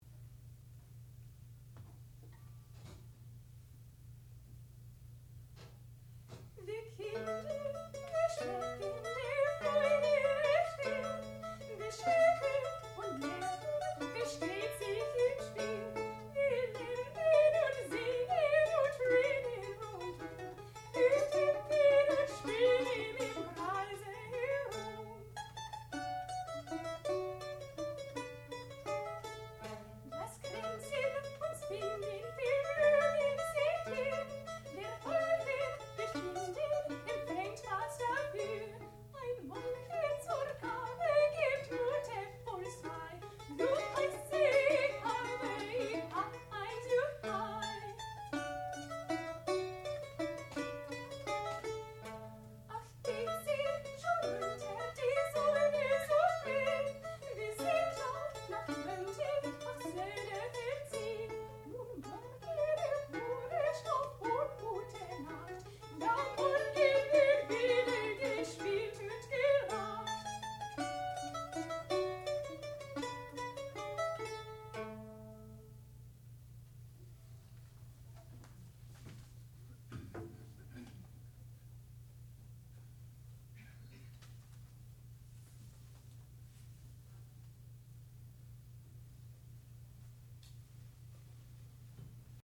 sound recording-musical
classical music
alto domra
mandolin
soprano